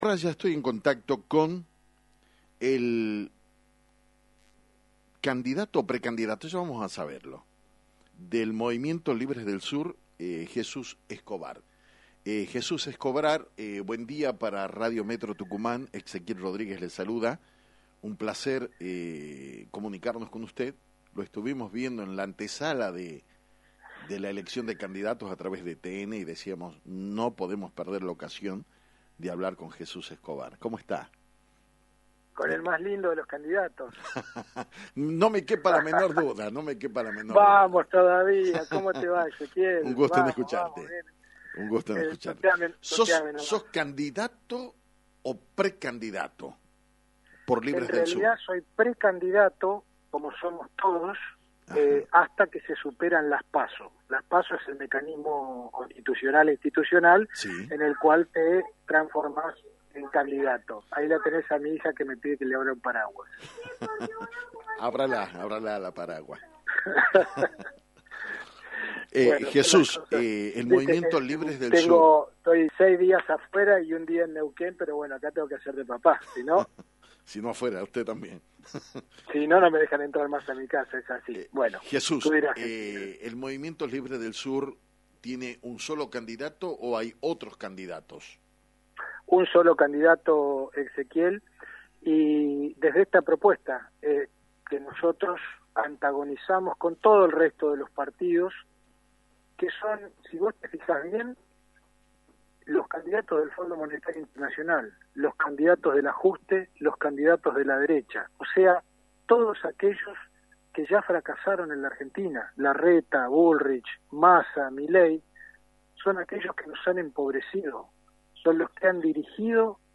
En dialogo exclusivo con Actualidad en Metro